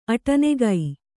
♪ aṭanegai